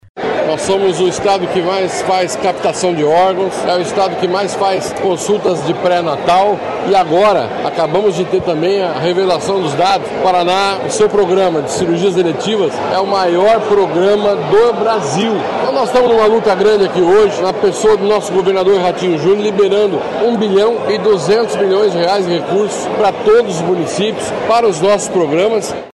Player Ouça Beto Preto, secretário de Saúde do Paraná